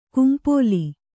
♪ kumpōli